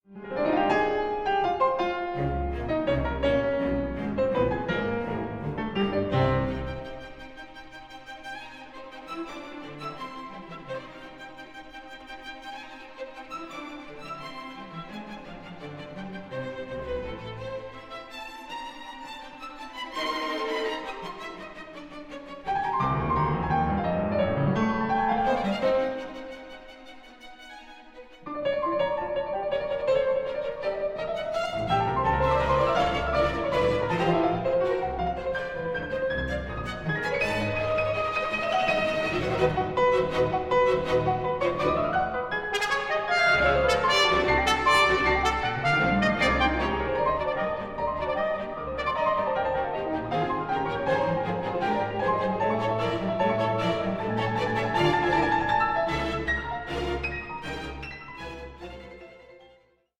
Moderato 1:28
and Strings bursts with irony and theatrical flair